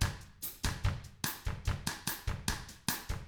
146BOSSAF1-L.wav